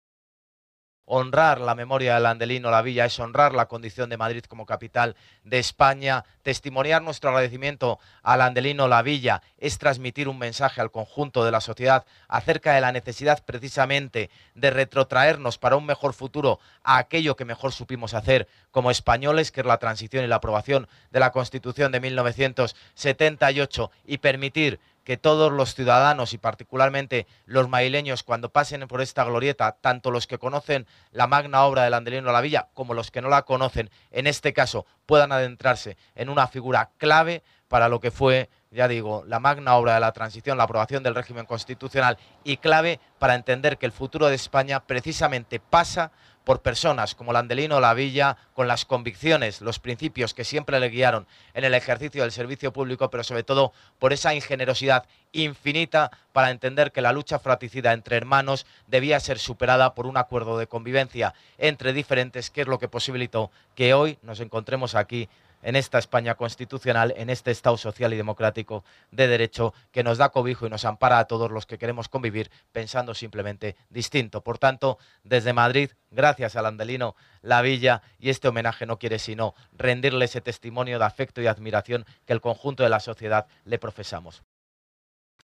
Durante la inauguración de la plaza que lleva el nombre de este jurista y político en el distrito de Fuencarral-El Pardo
Nueva ventana:Declaraciones del alcalde, José Luis Martínez-Almeida